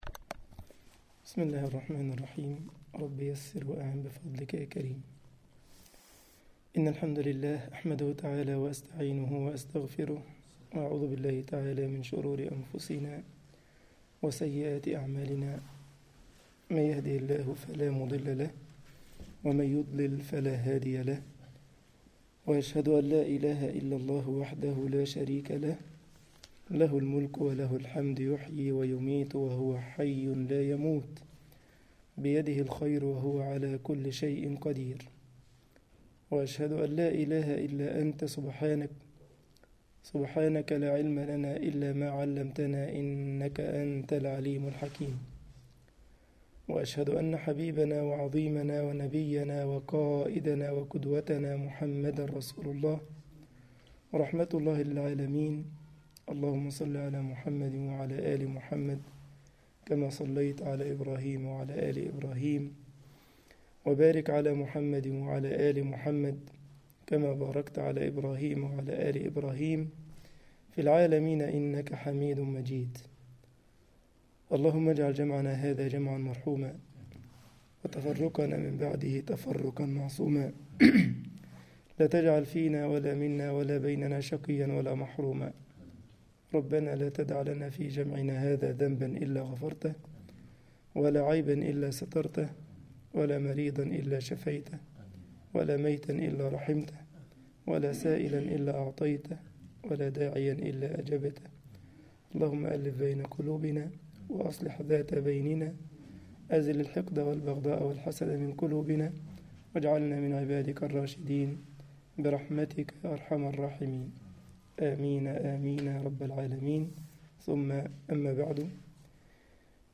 مسجد الجمعية الإسلامية بالسارلند ـ ألمانيا درس